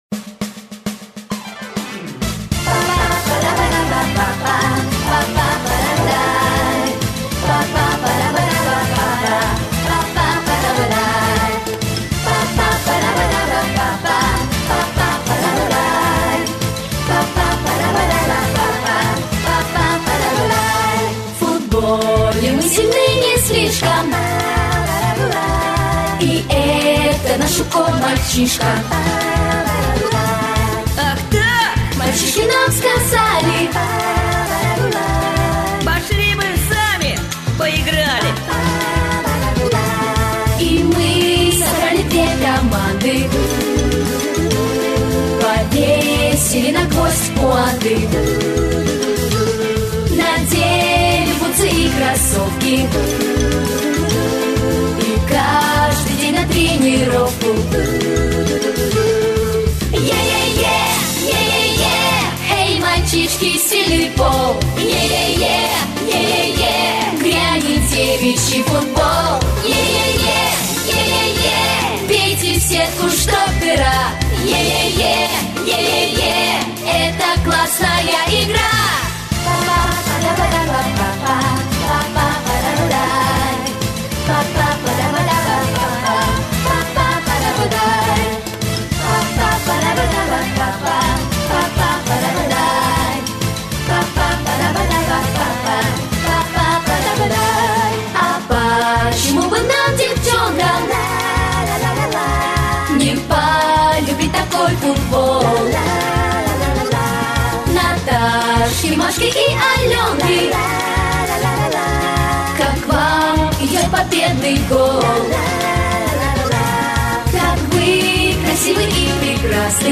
Аудиокнига Спортивные игры | Библиотека аудиокниг